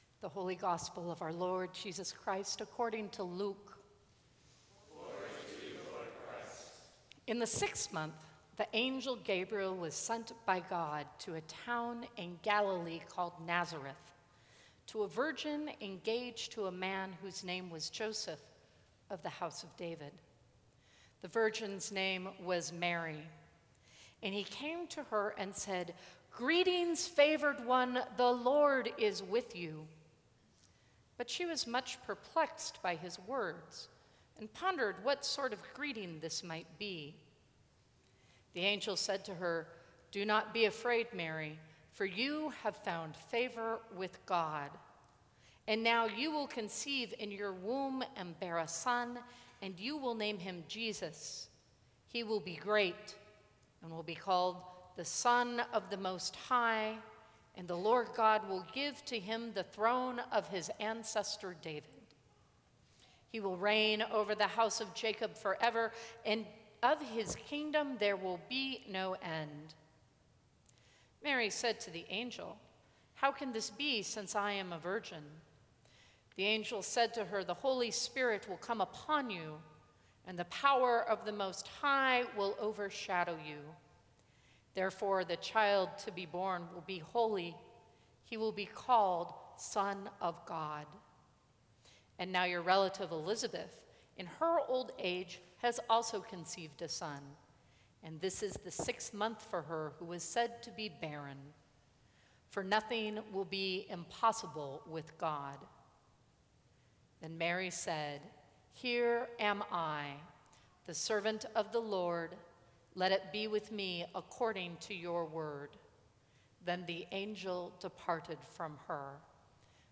Sermons from St. Cross Episcopal Church December 21, 2014.